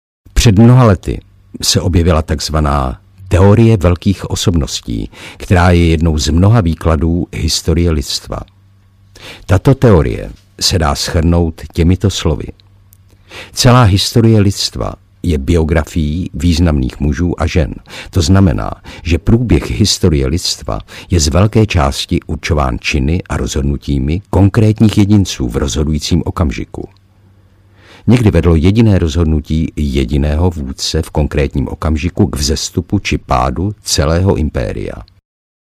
Jak být skvělým vůdcem a předběhnout konkurenci audiokniha
Ukázka z knihy